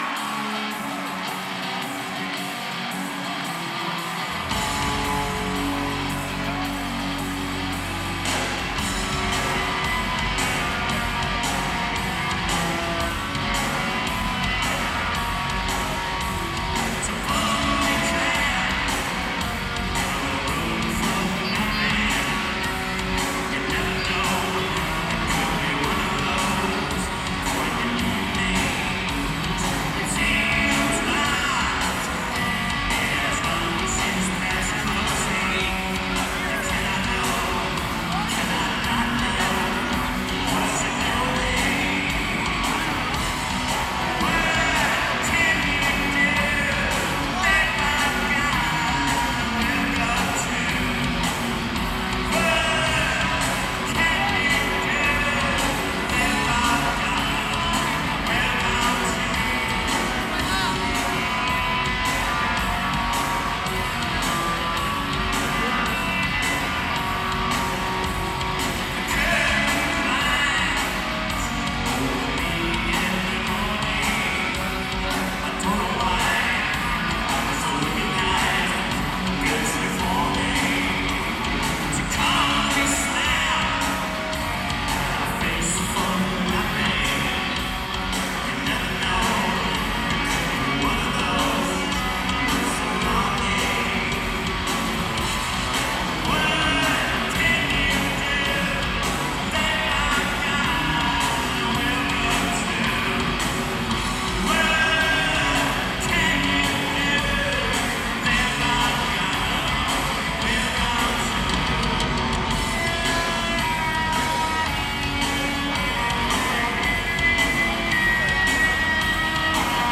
Live In 1992